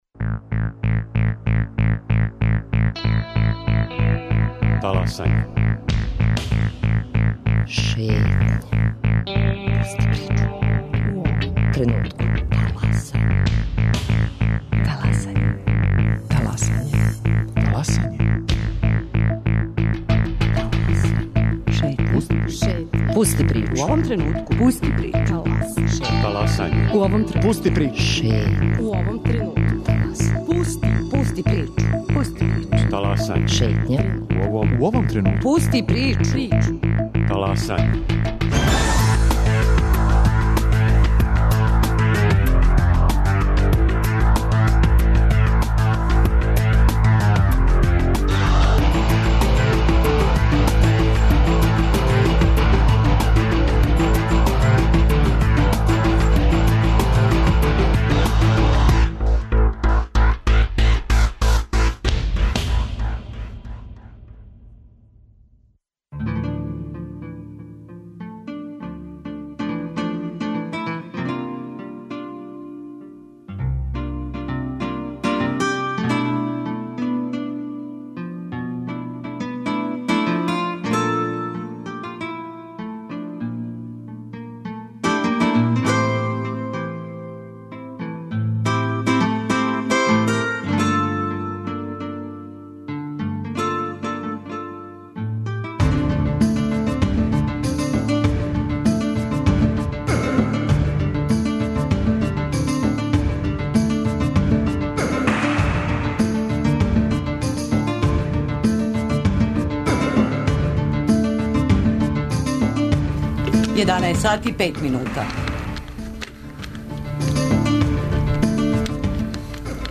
[ детаљније ] Све епизоде серијала Аудио подкаст Радио Београд 1 Ромска права у фокусу Брисела Дипломатски односи и позиционирање Србије у међународном поретку Вести из света спорта Хумористичка емисија Хумористичка емисија